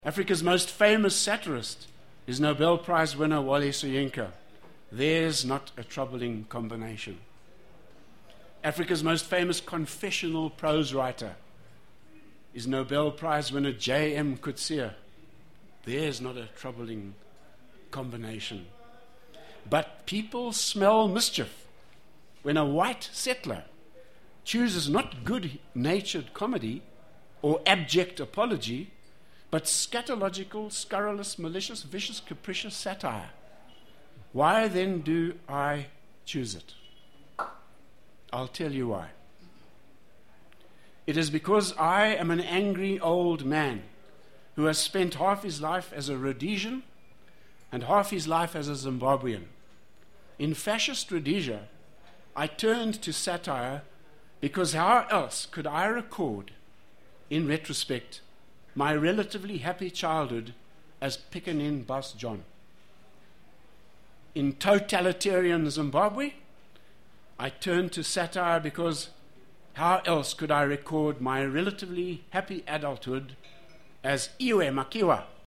Satire - Book Café discussion
at a discussion on satire at Harare's Book Café on Thursday 27 November